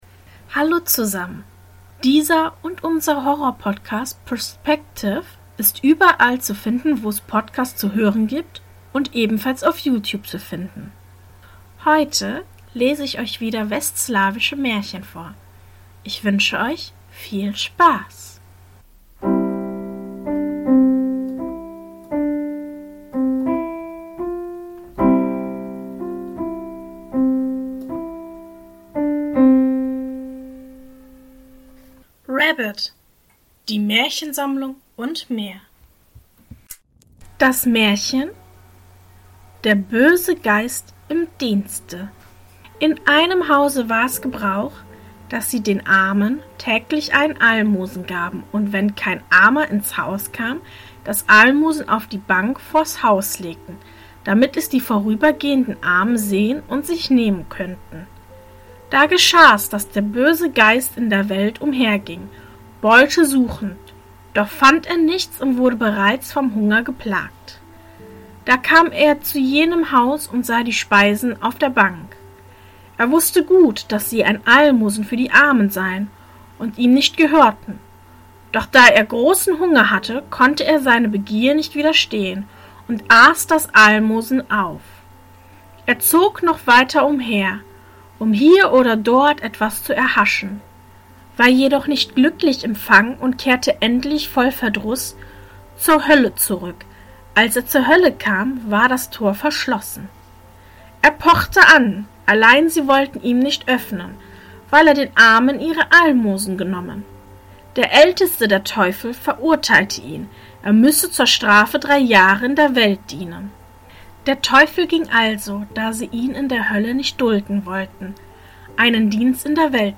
In diesem Podcast erzähle ich Euch verschiedene Märchen und möchte Euch einladen zu träumen und die Zeit gemeinsam zu genießen. Die Märchen werden aus aller Welt sein und sollen Euch verleiten, dem Alltag etwas zu entfliehen.